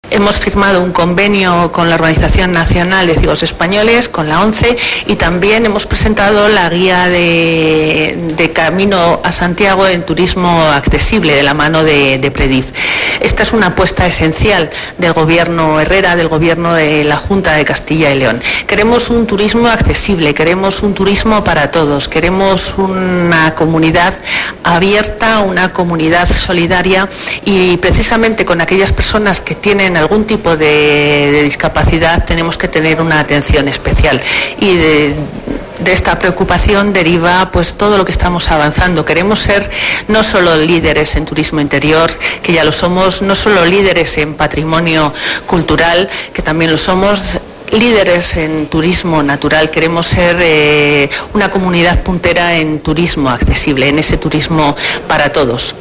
según explicaba al respecto la consejera María Josefa García.